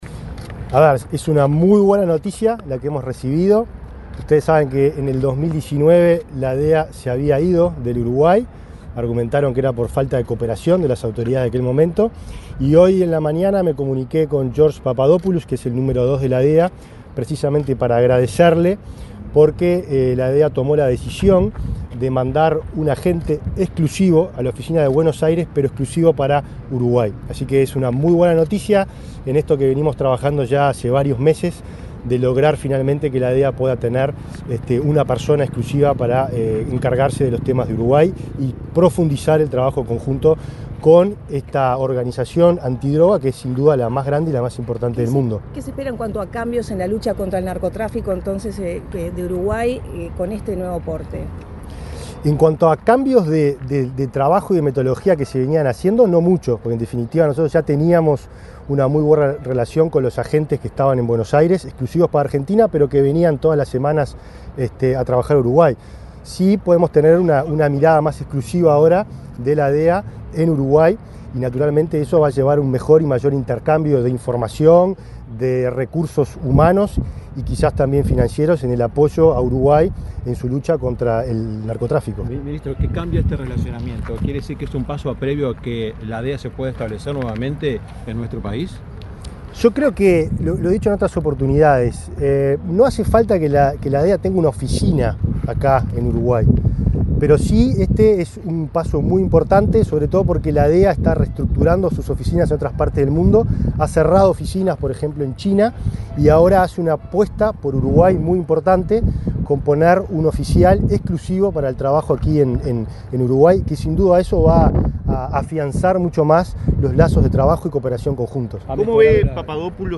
Declaraciones del ministro del Interior, Nicolás Martinelli
El ministro del Interior, Nicolás Martinelli, dialogó con la prensa, luego de participar en la entrega de 58 motos a la Zona IV, en el barrio Cerro,